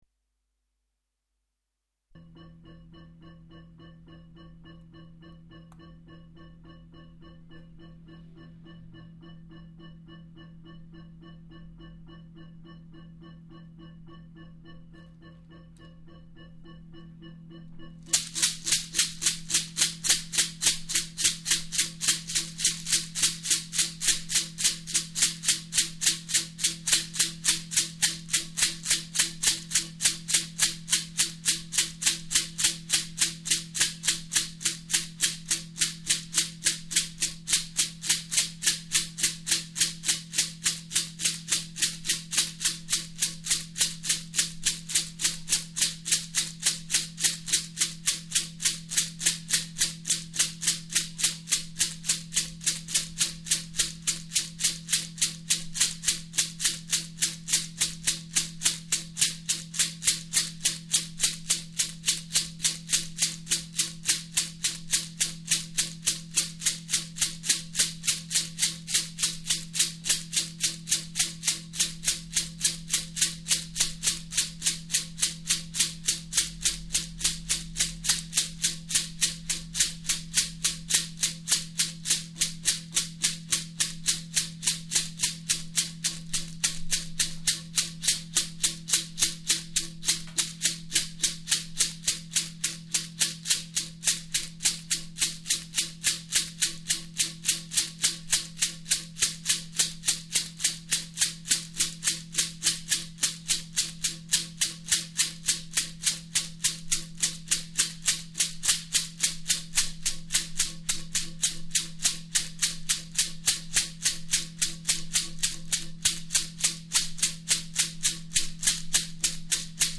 Abbildung des Versuchs einer Ekstase bei 210bpm